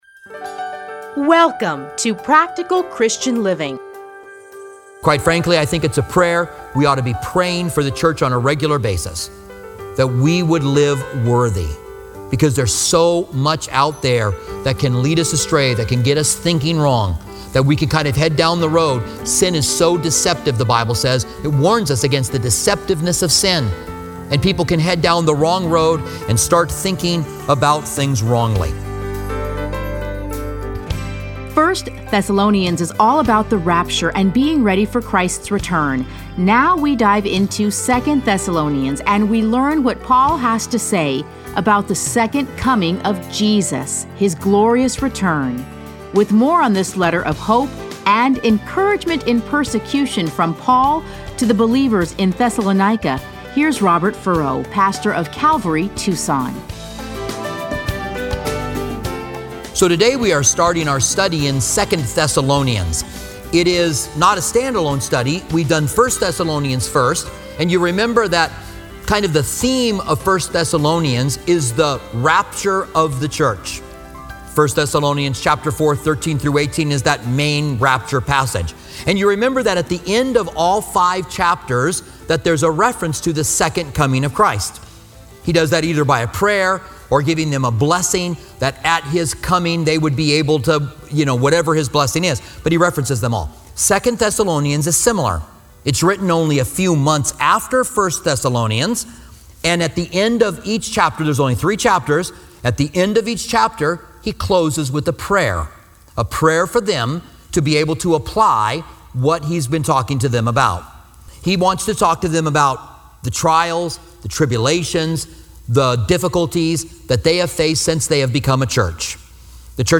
Listen to a teaching from 2 Thessalonians 1:1-12.